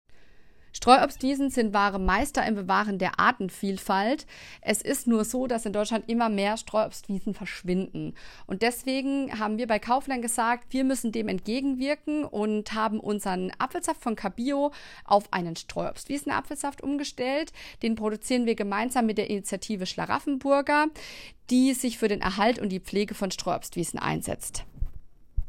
O-Ton Streuobstwiesenapfelsaft